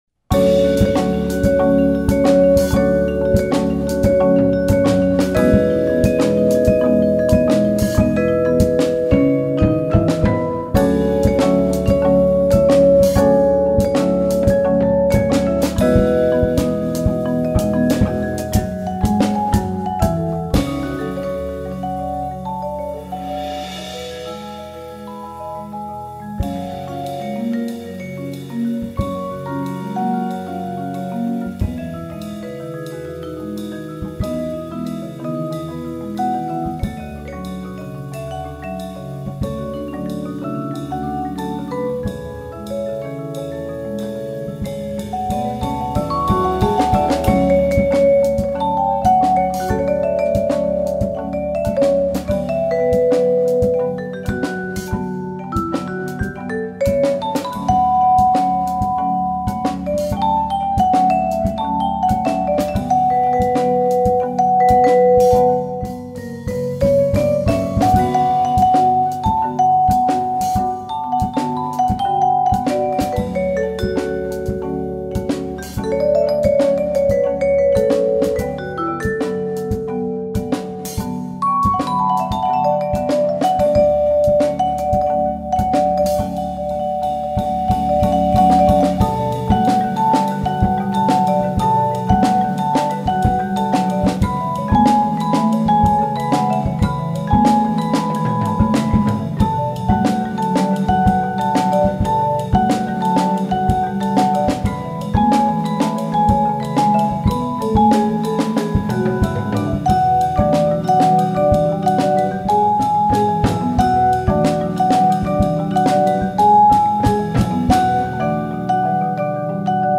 Genre: Percussion Ensemble
Player 1: Vibraphone, 3 Drum Set Toms, Shaker, 4 mallets
Player 4: 5-octave Marimba, Kick Drum with pedal, 4 mallets